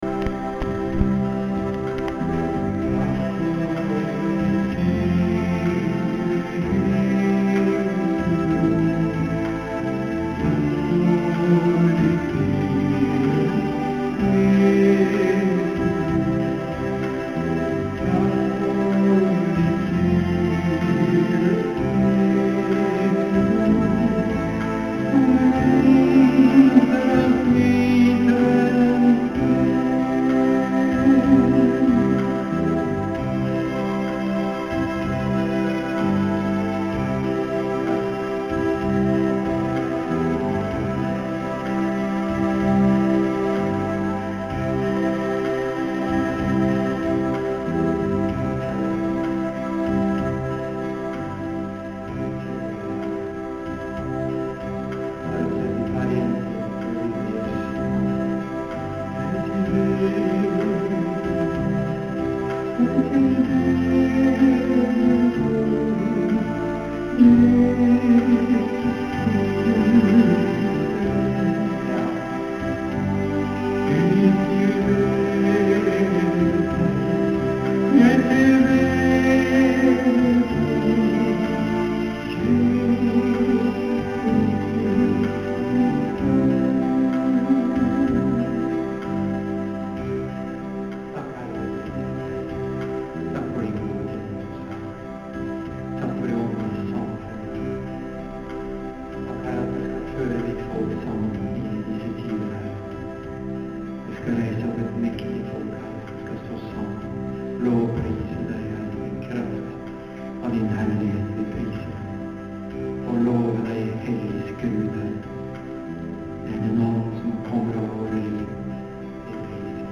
MARANATA Oslo søndag 10.5.2009. Kl 18.
Tale
Tyding av tungetale